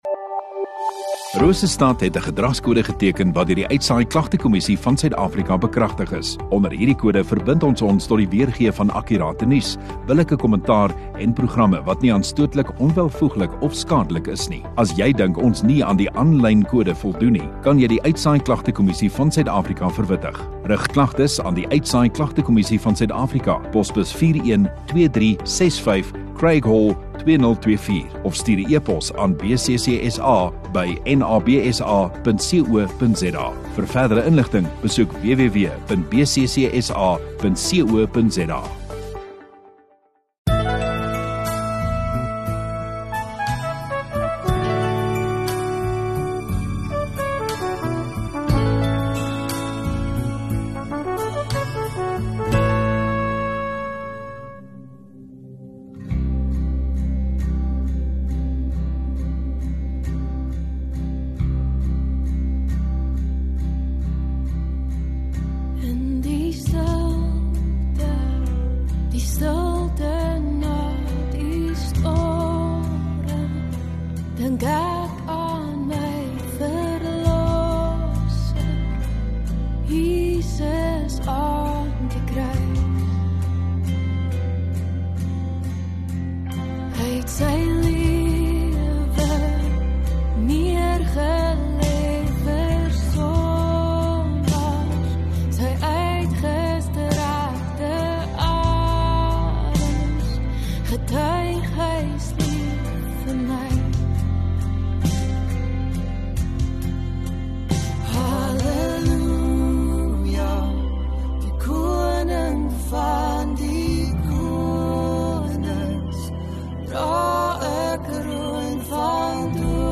23 Feb Sondagoggend Erediens